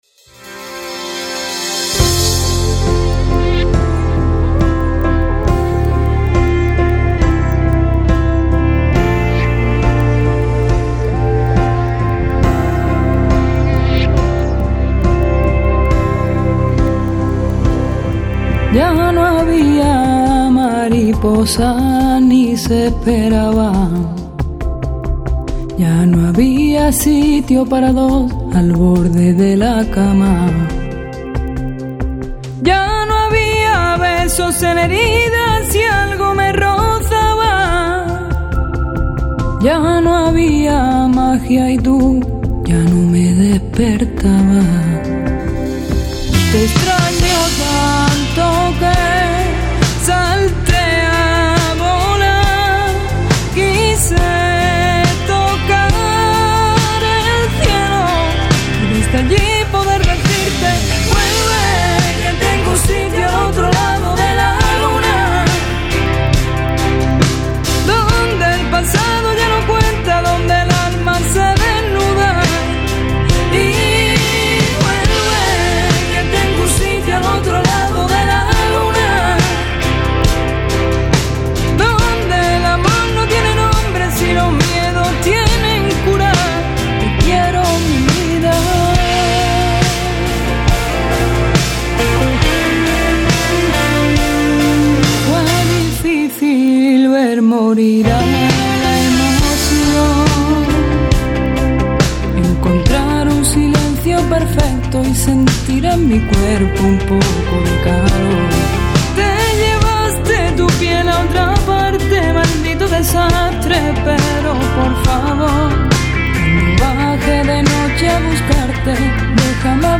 ha venido a presentar en los estudios de Radio TeleTaxi